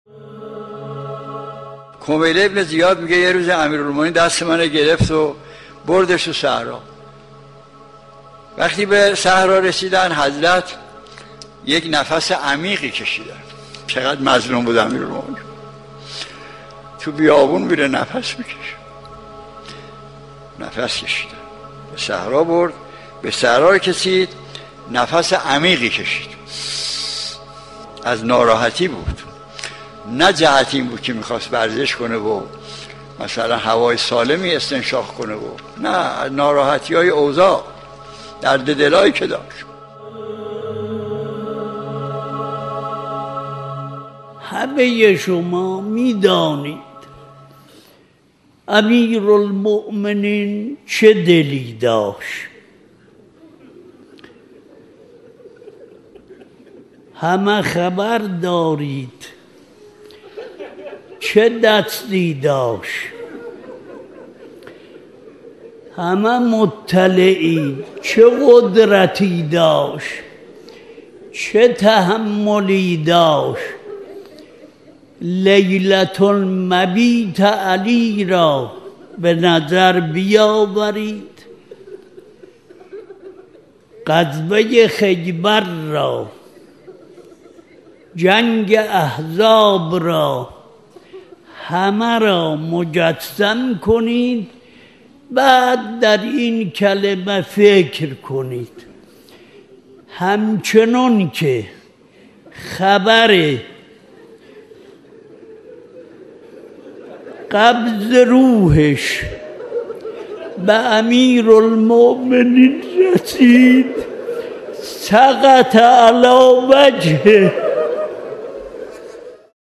ایکنا به مناسبت ایام سوگواری شهادت دخت گرامی آخرین پیام‌آور نور و رحمت، مجموعه‌ای از سخنرانی اساتید اخلاق کشور درباره شهادت ام ابی‌ها (س) با عنوان «ذکر خیر ماه» منتشر می‌کند. قسمت سیزدهم «اندوه امیرالمؤمنین(ع) در فقدان ریحانة النبی(س)» را در کلام آیت‌الله مجتهدی تهرانی(ره) و آیت‌الله وحید خراسانی می‌شنوید.